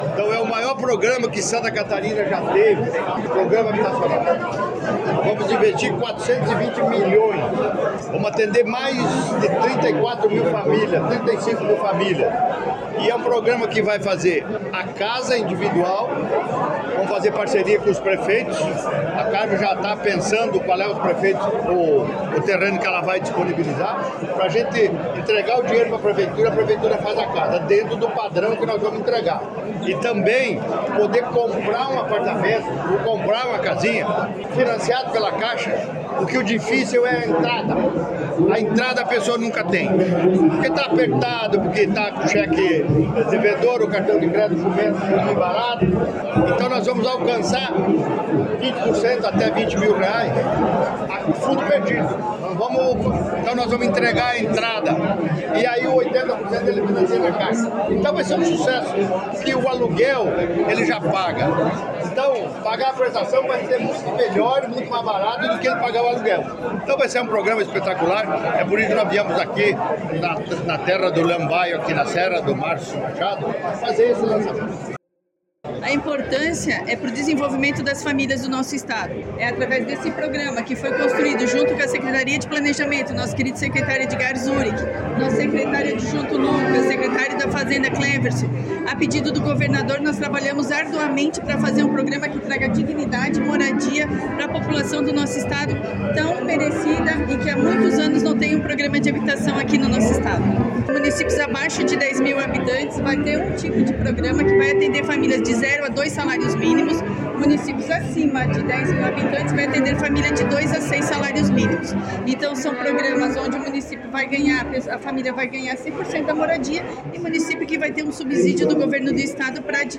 O governador lembra que, além de atender as demandas de habitação, o programa vai movimentar a economia de Santa Catarina com criação de postos de trabalho:
A secretária Maria Helena Zimmermann, comenta que hoje 60% do déficit habitacional de Santa Catarina é de pessoas que têm gasto excessivo de aluguel:
O secretário do Planejamento, Edgard Usuy, fala sobre o modelo prospecto: